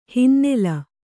♪ hinnela